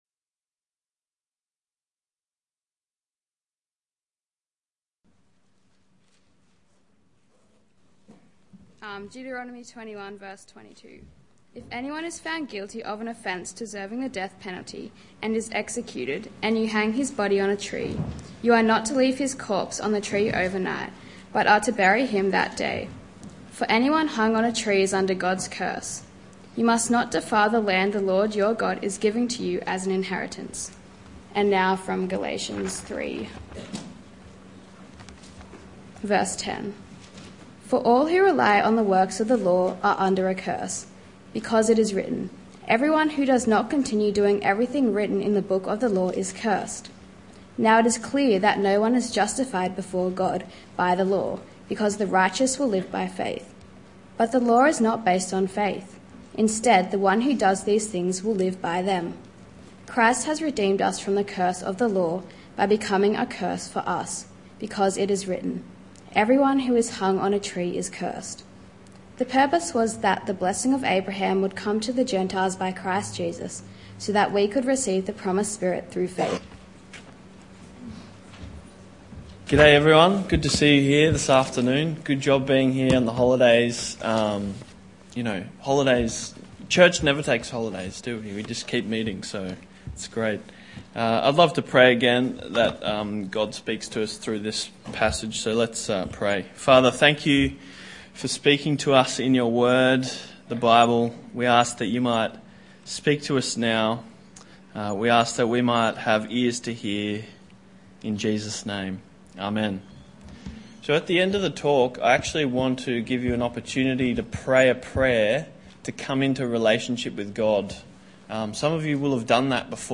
Youth Church